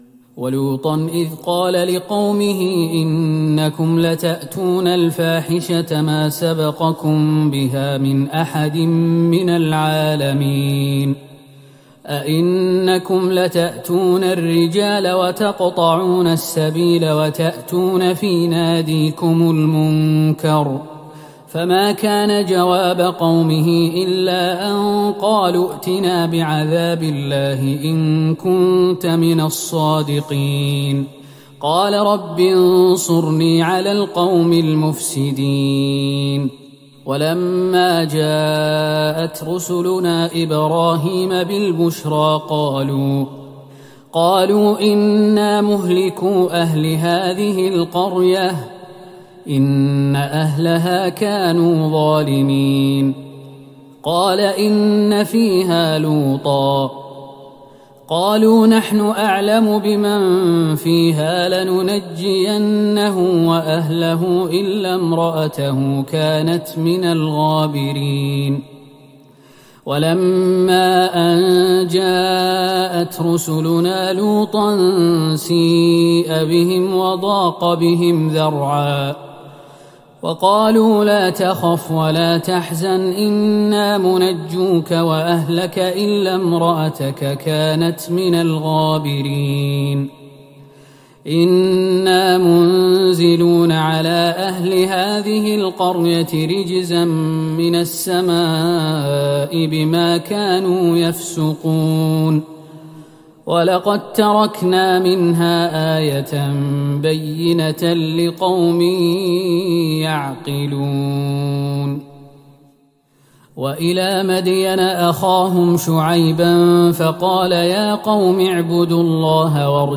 تراويح ٢٤ رمضان ١٤٤١هـ من سورة العنكبوت { ٢٨-٦٩ } والروم { ١-٤٥ } > تراويح الحرم النبوي عام 1441 🕌 > التراويح - تلاوات الحرمين